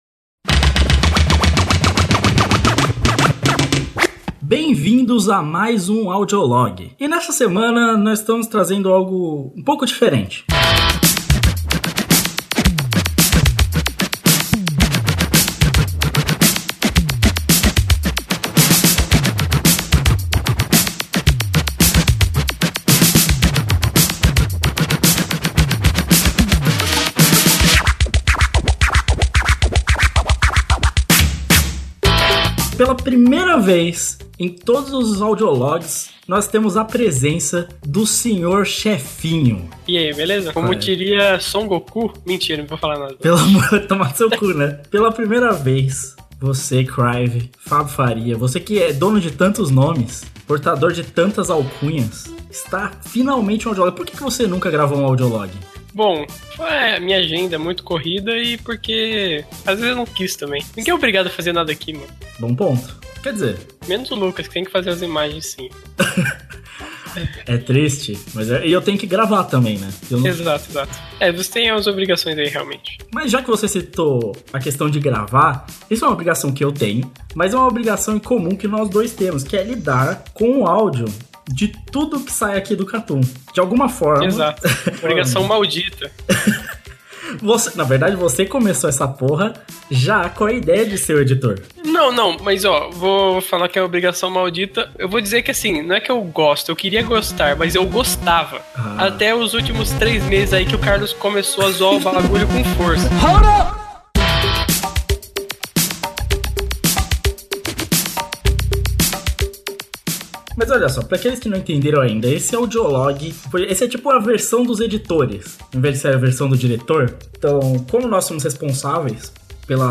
Ao passo que a ideia erá dar dicas sobre edição de podcast, os editores do Katoon se unem para dar dicas ao mesmo tempo que destilam seu ódio.